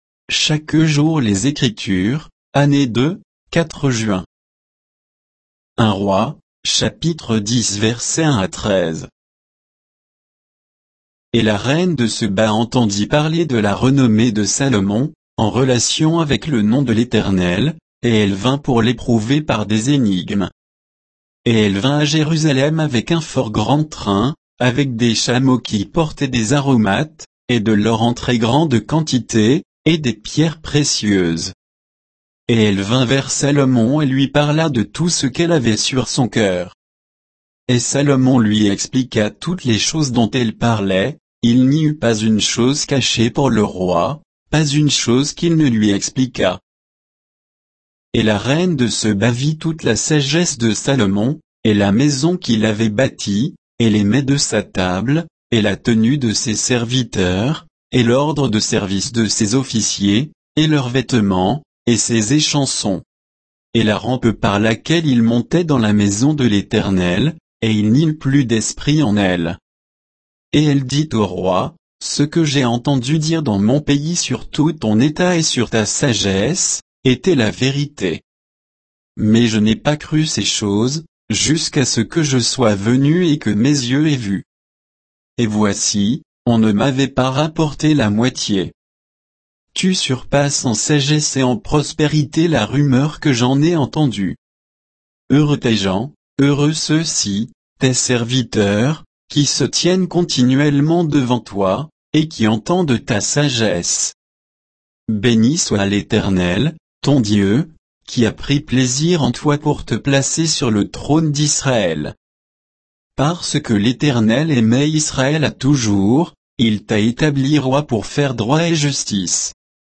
Méditation quoditienne de Chaque jour les Écritures sur 1 Rois 10